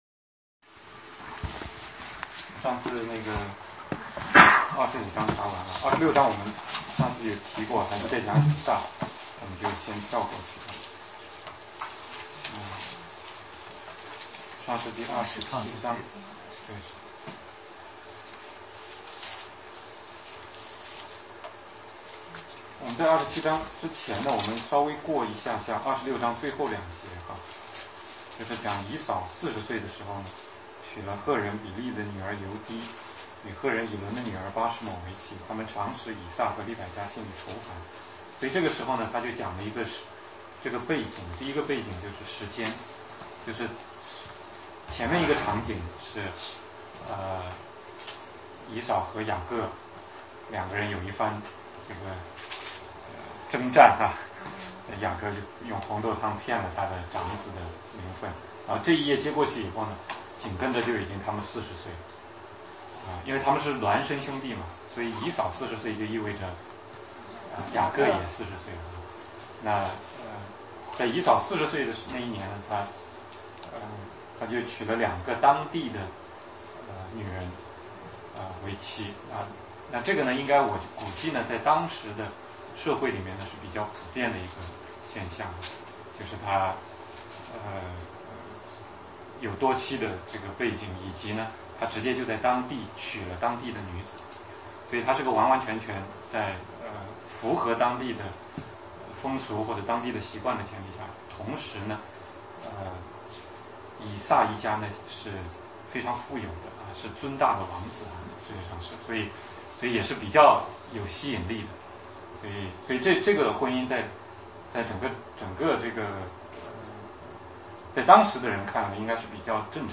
16街讲道录音 - 创世纪 27 雅各骗取以撒的祝福